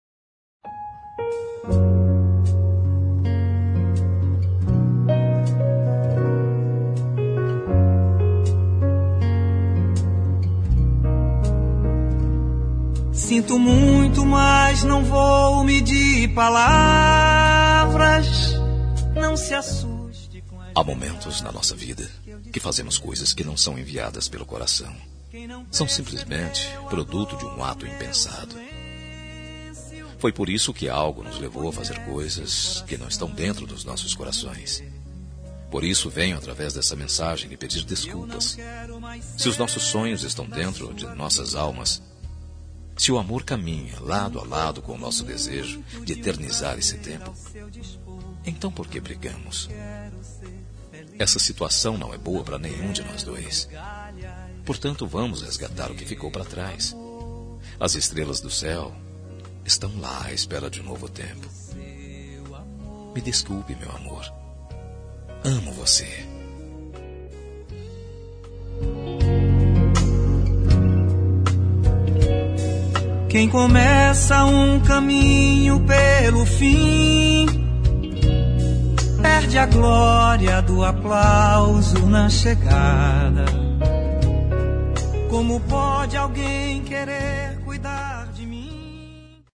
Telemensagem de Desculpas – Voz Masculina – Cód: 399